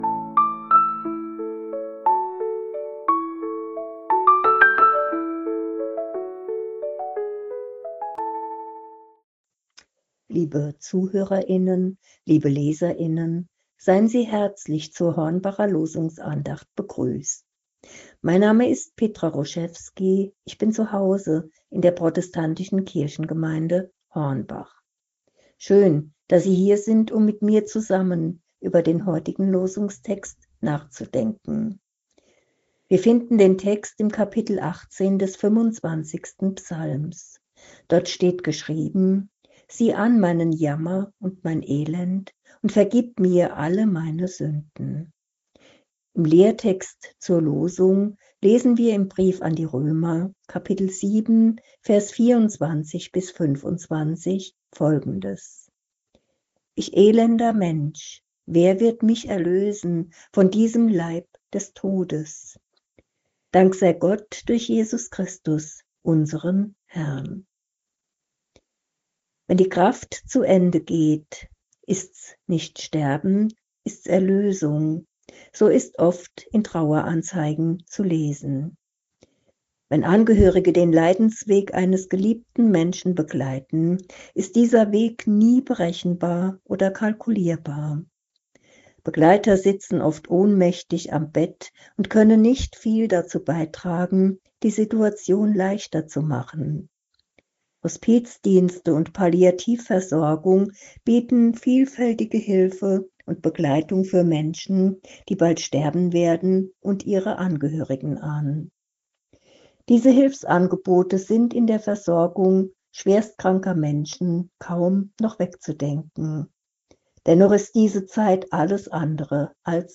Losungsandacht für Freitag, 20.03.2026